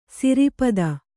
♪ siri pada